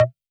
Index of /musicradar/retro-drum-machine-samples/Drums Hits/WEM Copicat
RDM_Copicat_SY1-Perc04.wav